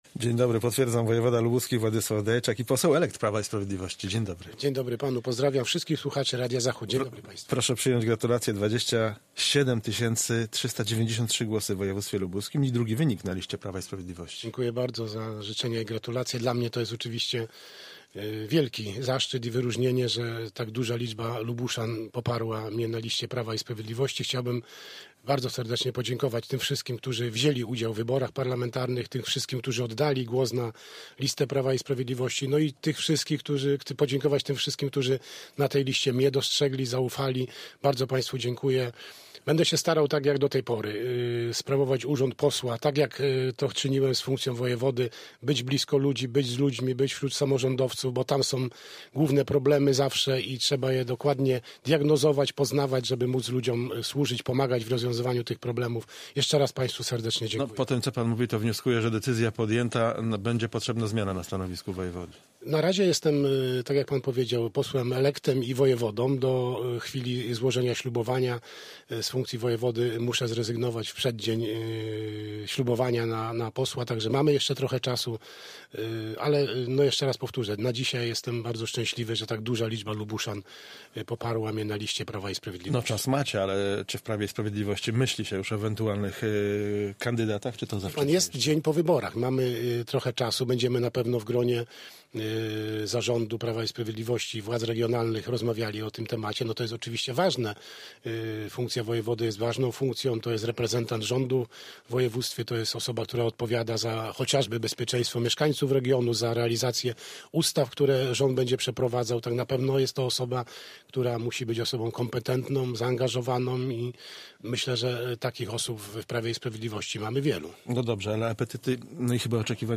Z wojewodą lubuskim, posłem elektem rozmawia